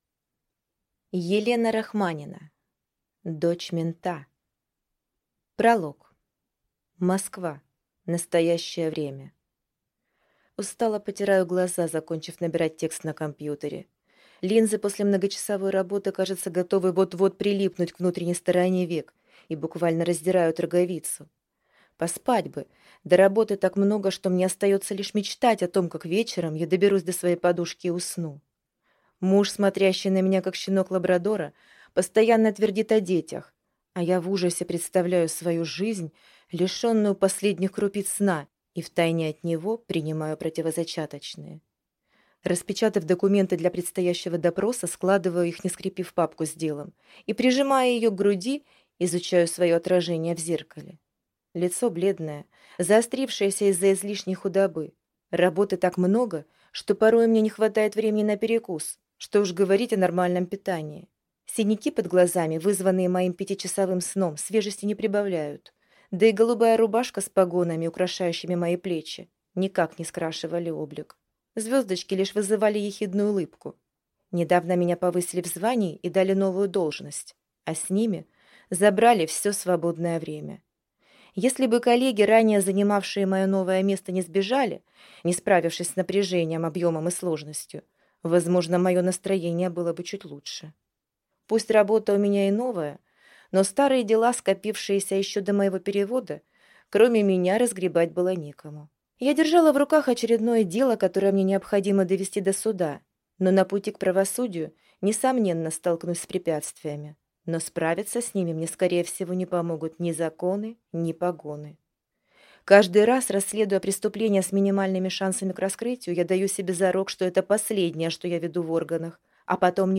Аудиокнига Дочь мента | Библиотека аудиокниг